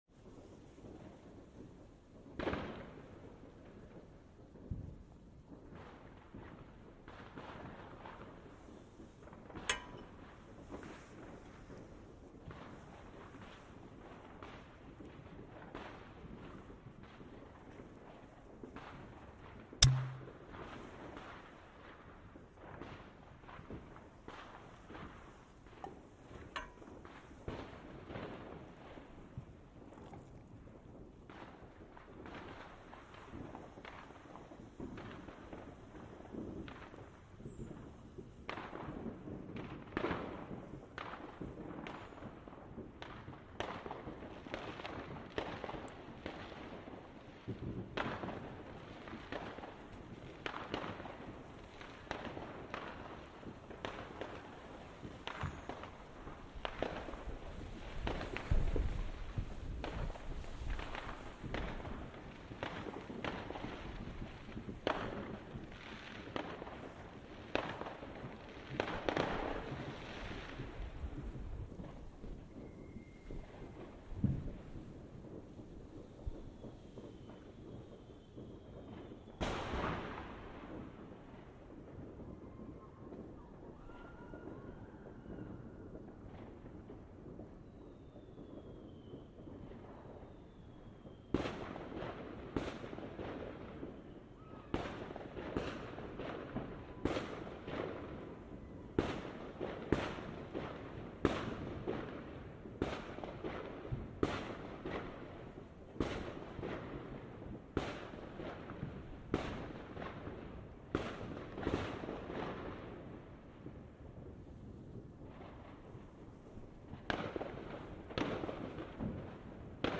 Lots of fireworks from my garden at New Year 2021-2022
This binaural recording shows the build-up to New Year with increasing amounts of fireworks, then lots of very loud fireworks close by after midnight and people cheering and wishing each other "happy new year" in the distance, then the number of fireworks decreasing at the end. It was recorded with my iPhone in conjunction with the Sennheiser Ambeo Smart Headset, which gives a brilliant stereo effect.